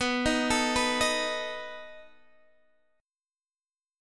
Listen to B7 strummed